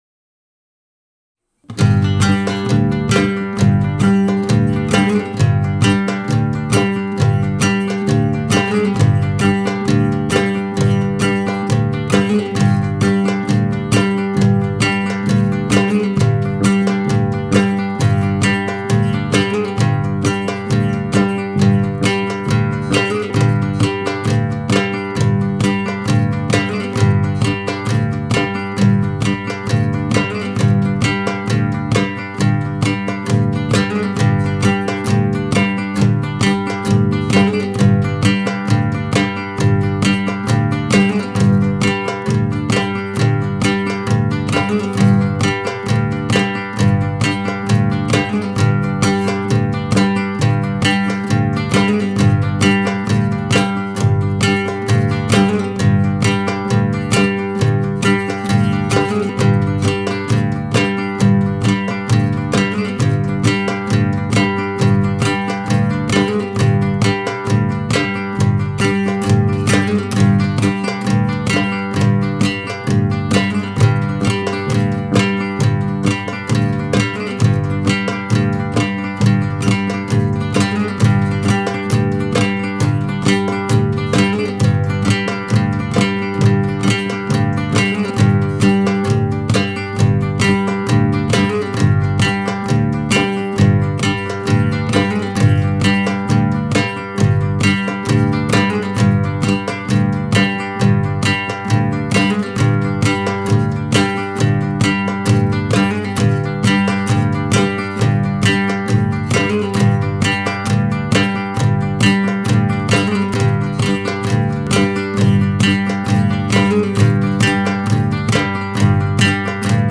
La Zambra vient des Maures, les mélodies sont arabisé.
Pour jouer sur le « Backing track »